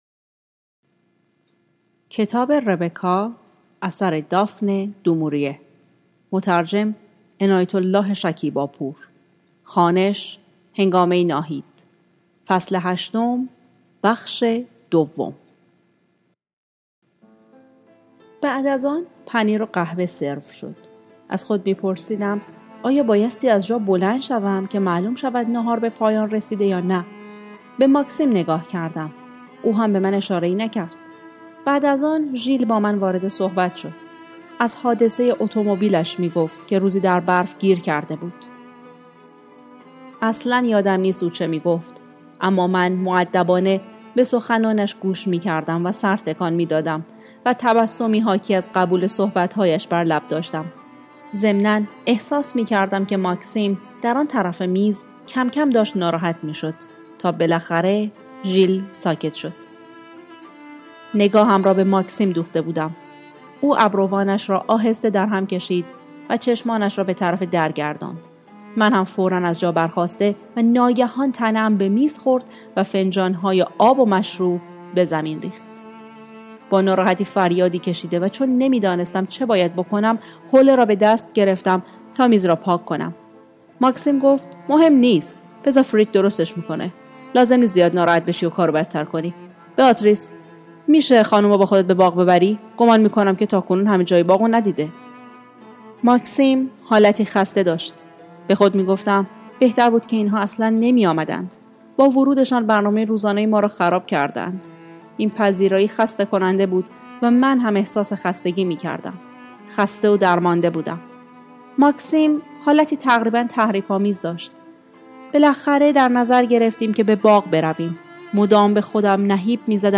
کتاب‌خوانی – ربکا (فصل هشتم – بخش دوم)